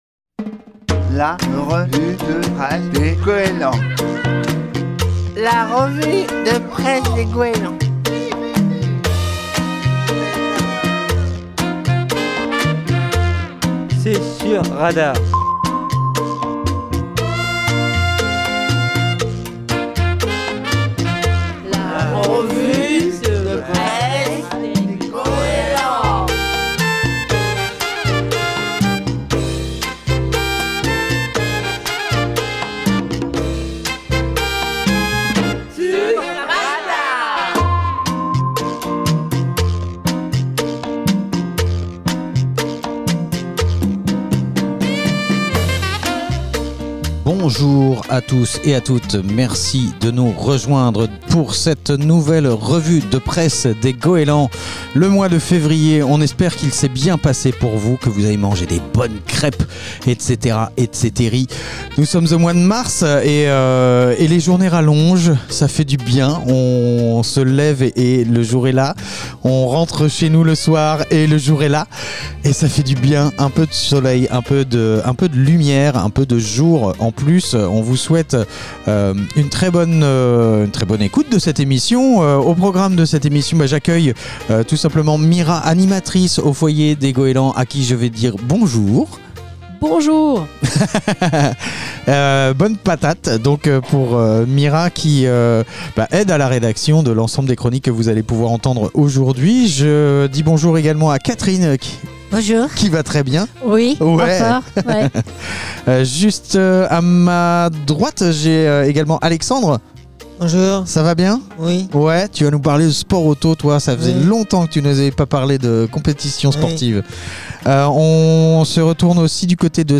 Les usagers du foyer d'activités des Goélands de Fécamp font leur revue de presse tous les 2ème mardis de chaque mois sur Radar